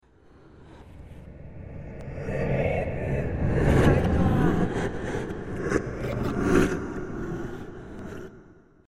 Пролетающие мимо призраки (звук фэг)
proletaiushchie-mimo-prizraki-zvuk-feg.mp3